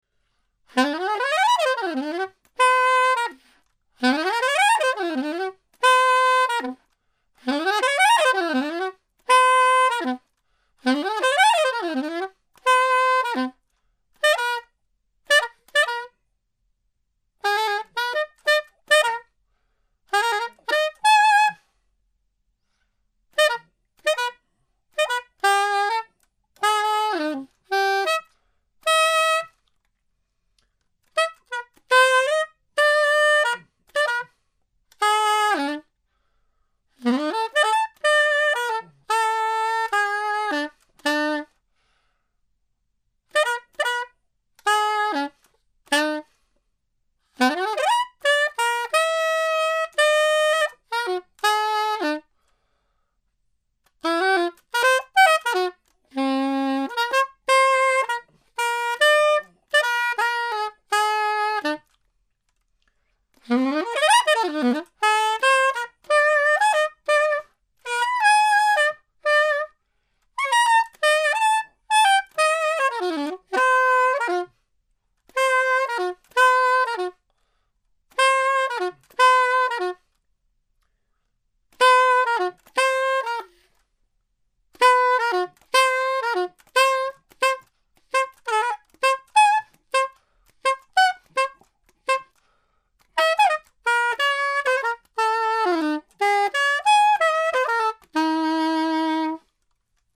Improvisation (01:40)